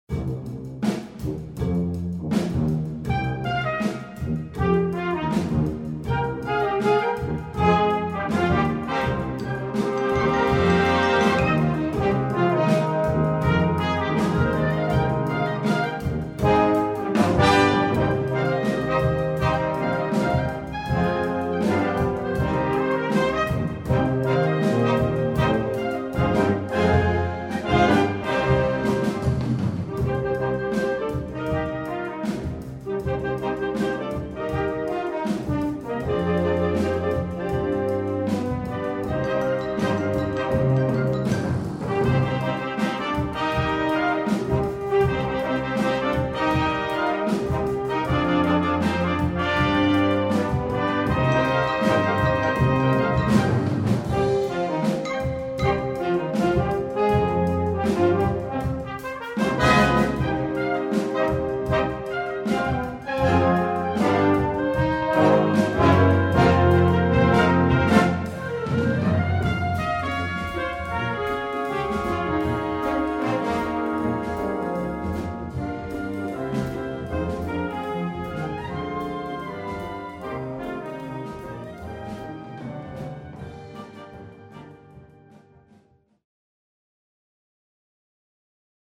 Recueil pour Harmonie/fanfare